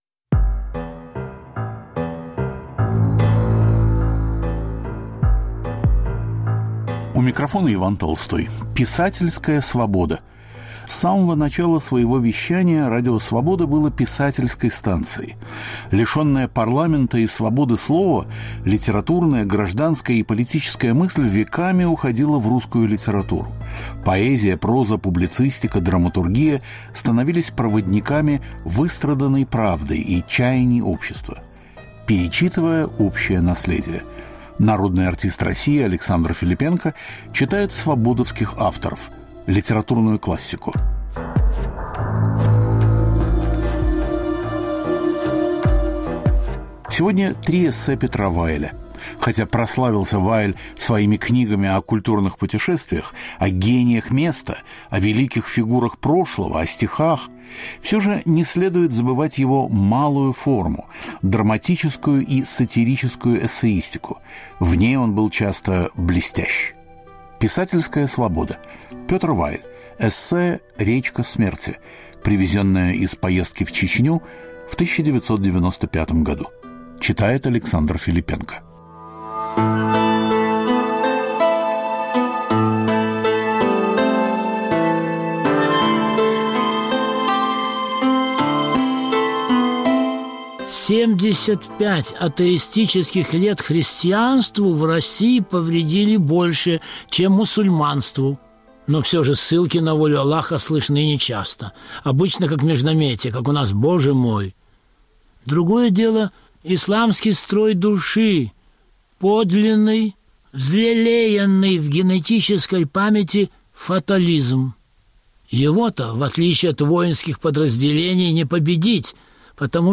Писательская свобода. Народный артист России Александр Филиппенко читает авторов Радио Свобода. Звучат три эссе Петра Вайля
В чтении А. Филиппенко прозвучат три коротких очерка.